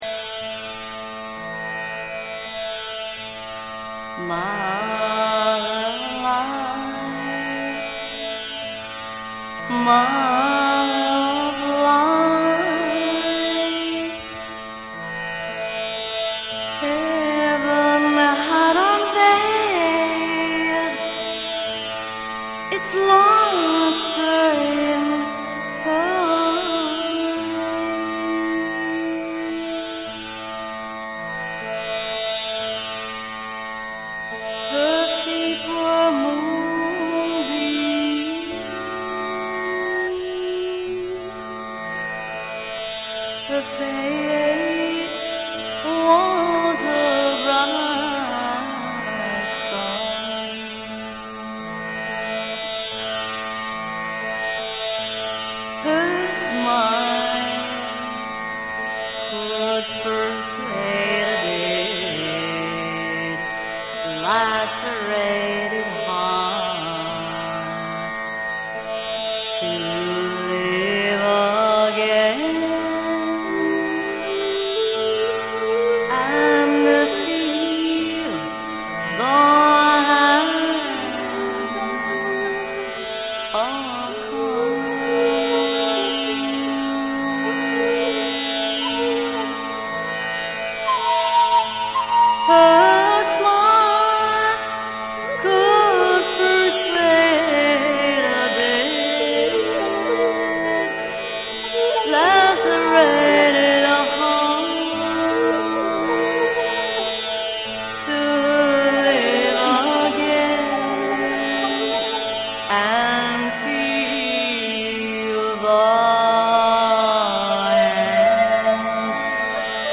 ジャズ、エレクトロニクス、詩的な語りが溶け合う、まさにECMならではの冬の記憶。